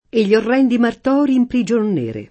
martorio [mart0rLo] o martoro [mart0ro] s. m.; pl. ‑ri — voce ant. per «tortura», ant. o poet. per «martirio»: messolo al martorio, ogni cosa fatta confessò [m%SSolo al mart0rLo, 1n’n’i k0Sa f#tta konfeSS0] (Boccaccio); E gli orrendi martòri in prigion nere [